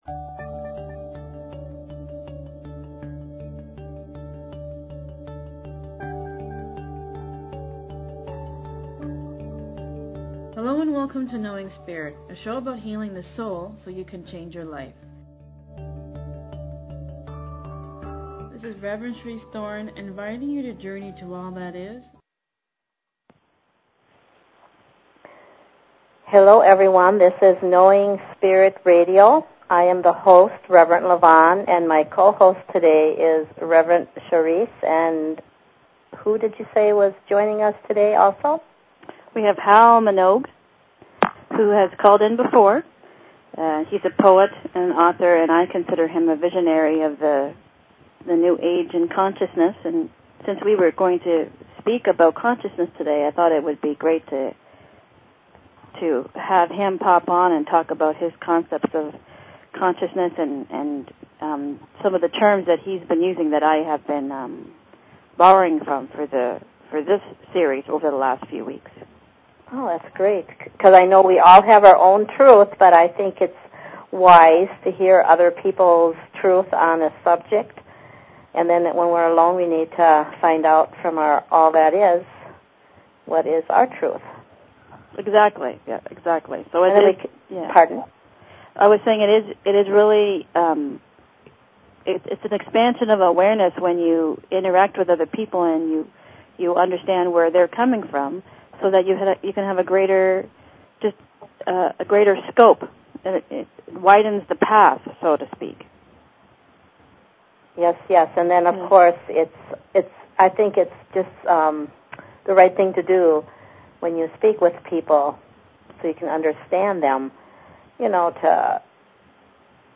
Talk Show Episode, Audio Podcast, Knowing_Spirit and Courtesy of BBS Radio on , show guests , about , categorized as